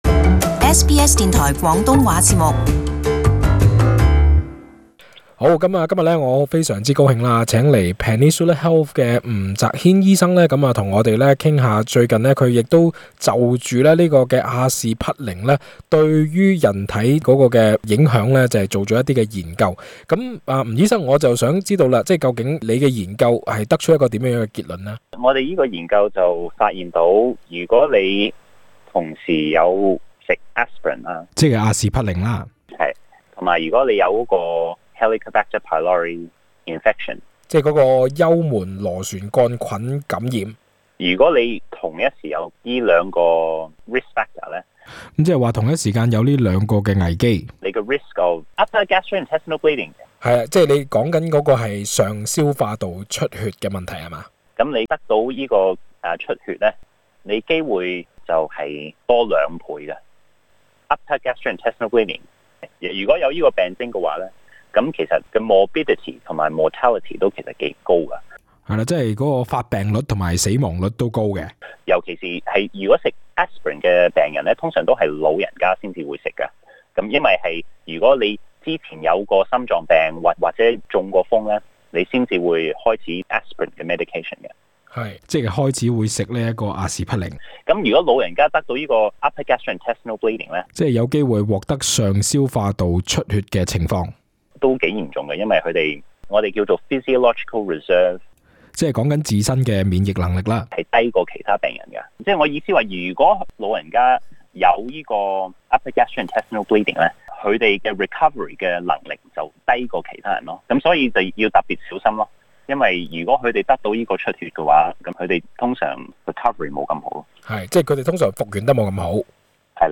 【特別專訪】阿士匹靈的作用與風險